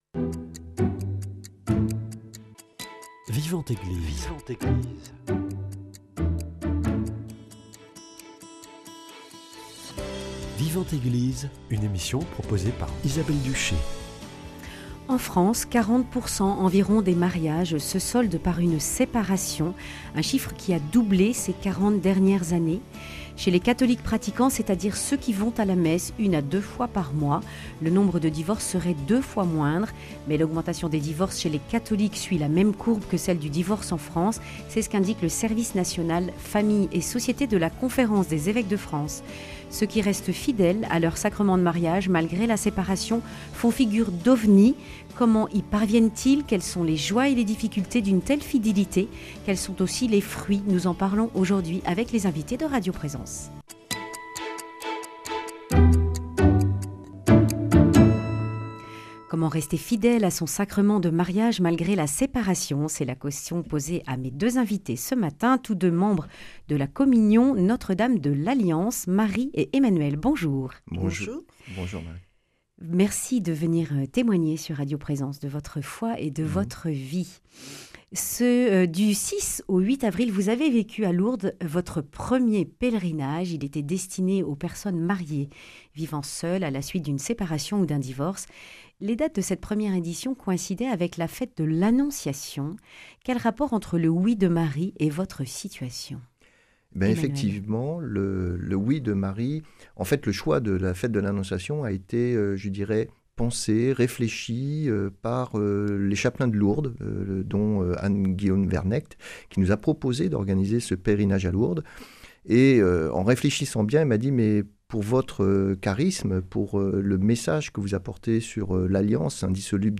Accueil \ Emissions \ Foi \ Vie de l’Eglise \ Vivante Eglise \ Comment rester fidèle à son mariage malgré la séparation ?